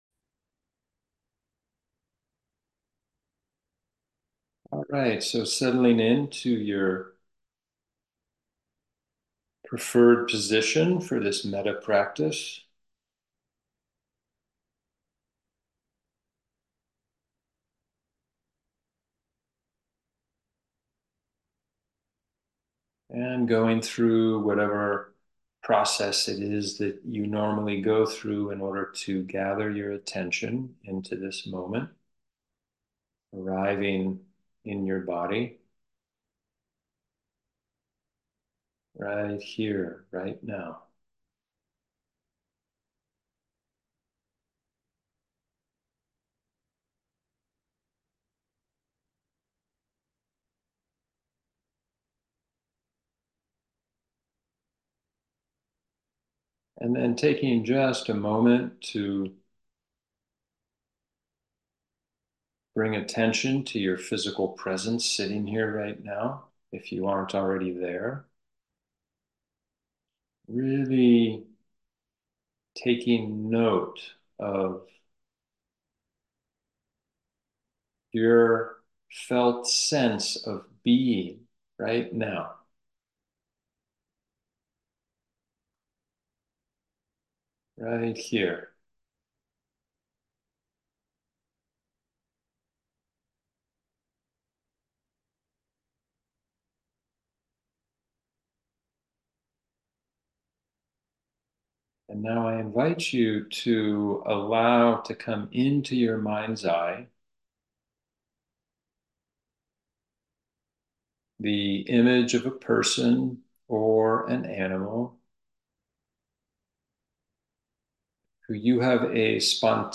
Loving-Kindness-Meditation.mp3